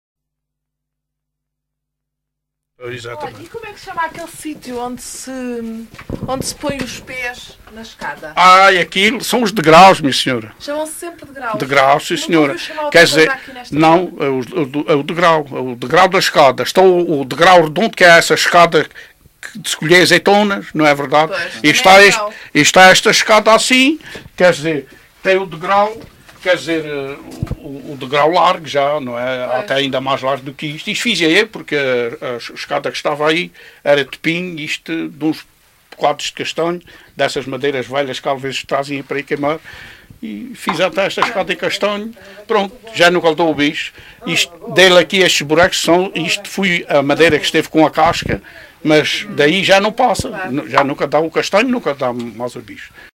LocalidadeCastelo de Vide (Castelo de Vide, Portalegre)